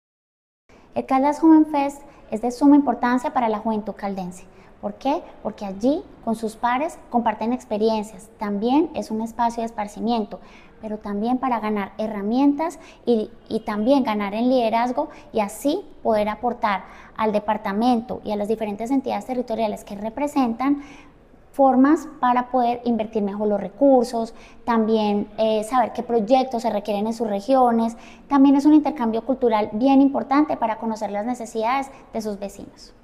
Sandra Patricia Álvarez Castro, secretaria de Integración y Desarrollo Social de Caldas.
dra-Patricia-Alvarez-Castro-Secretaria-de-Integracion-y-Desarrollo-Social.mp3